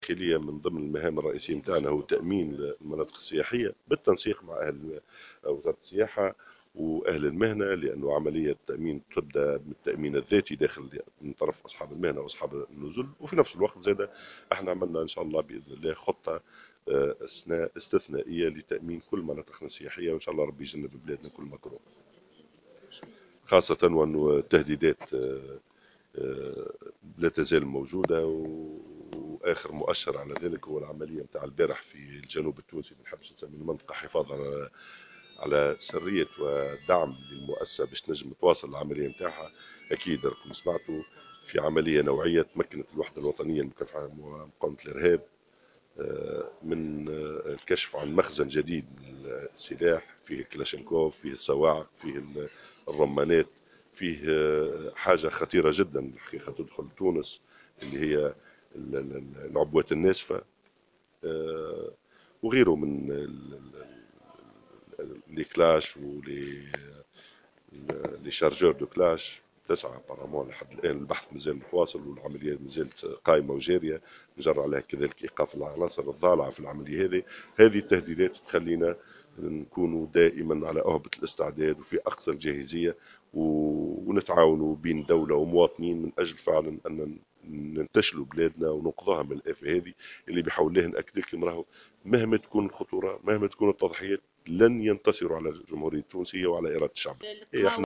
أكد وزير الداخلية الناجم الغرسلي في تصريح اعلامي اليوم الإثنين 30 نوفمبر 2015 أن من أبرز مهام وزارة الداخلية تأمين المناطق السياحية وذلك بالتنسيق مع أصحاب النزل والمهنيين مشيرا إلى أن الوزارة وضعت خطة استثنائية هذا العام لتأمين كل المناطق السياحية خاصة وأن التهديدات مازالت موجودة .